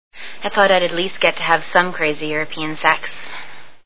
Euro Trip Sound Bites